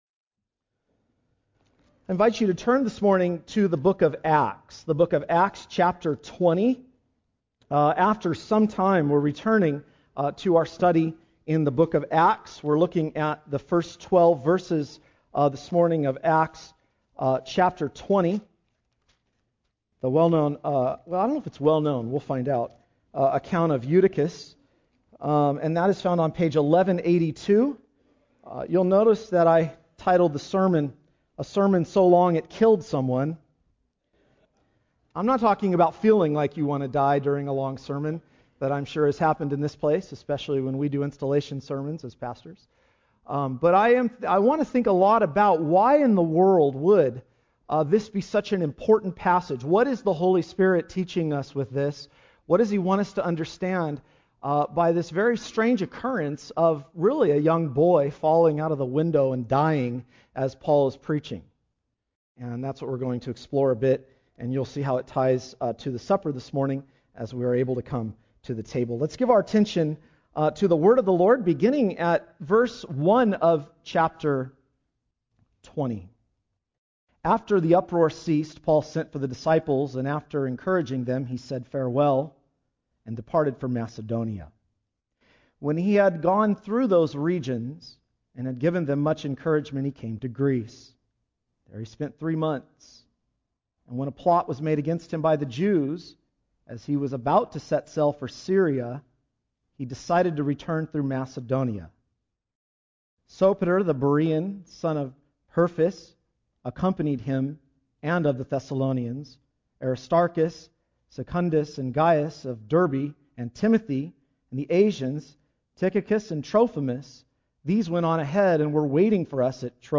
A Sermon So Long It Killed Someone